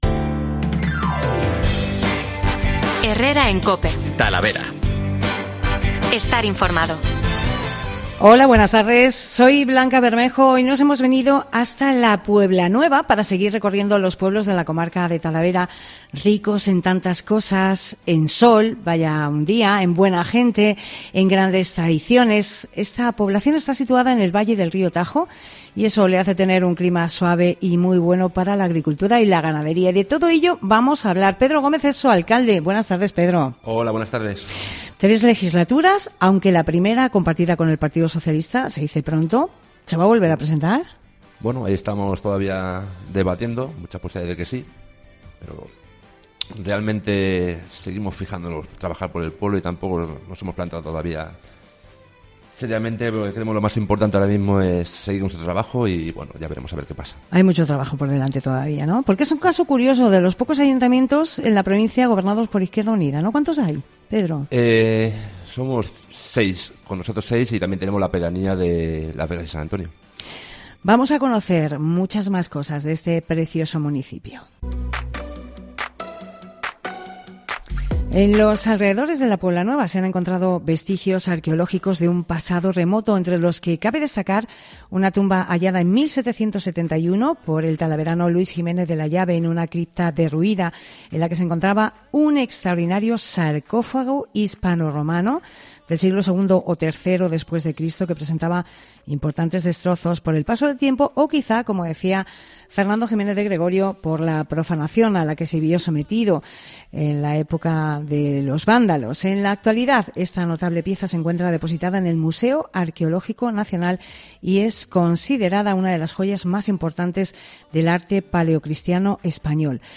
AUDIO: Salón de plenos del Ayuntamiento de La Pueblanueva, desde donde se emitió Herrera en COPE TALAVERA